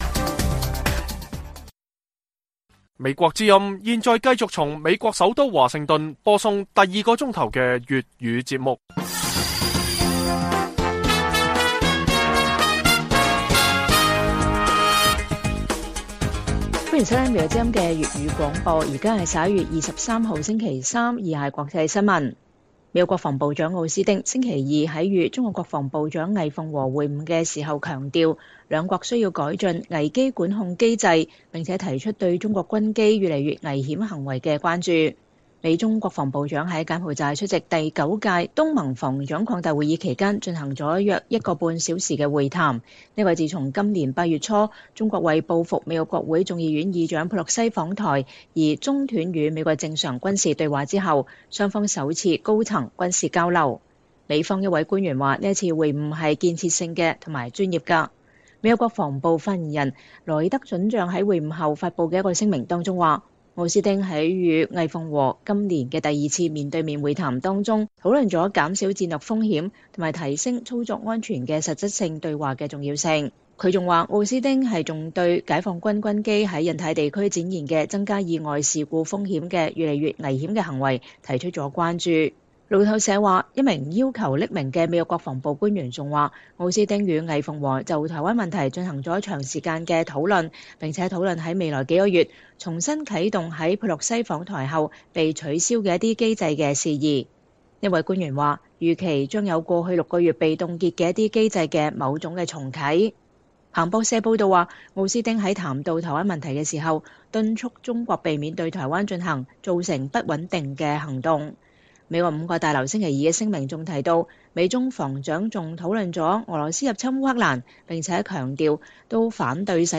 粵語新聞 晚上10-11點: 美中防長恢復對話